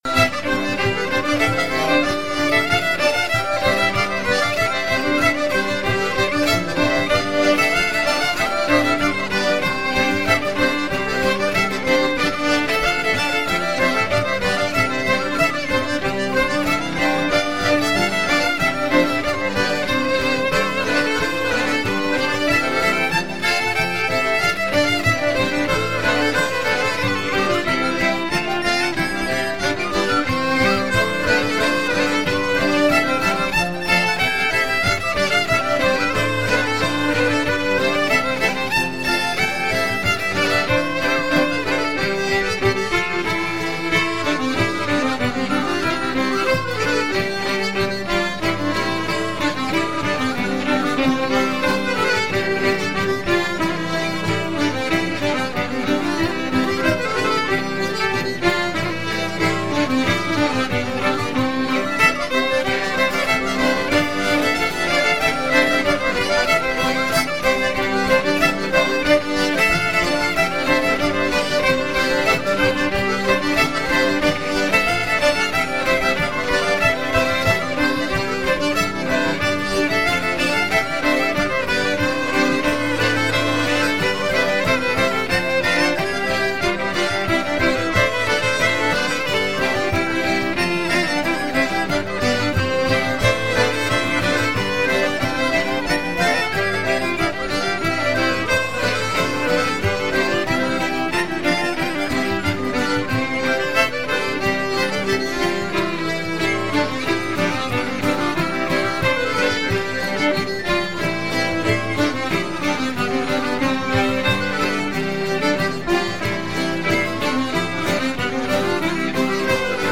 zpzpMusique Trad en Poitou
Bal aux Roches-Prémarie, salle du Clos des Roches